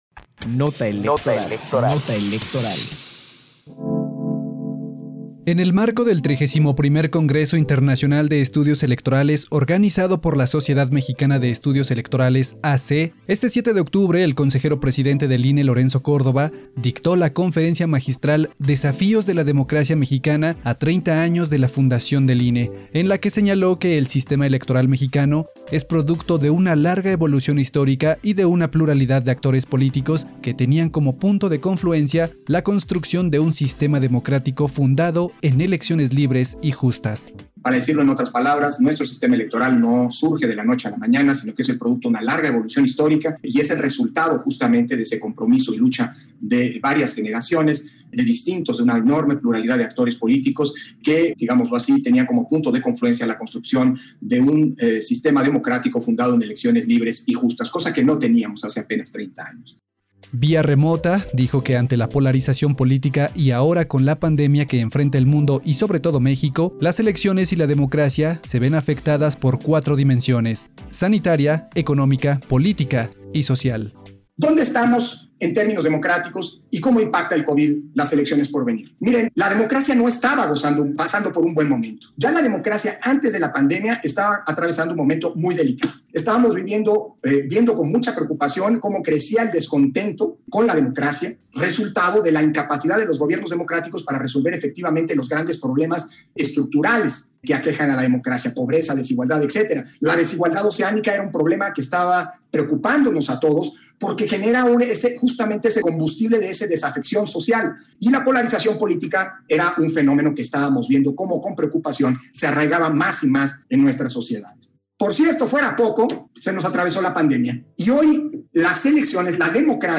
Conferencia Magistral Desafíos de la democracia mexicana a 30 años de la fundación del INE